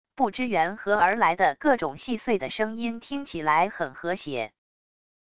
不知源何而来的各种细碎的声音听起来很和谐wav音频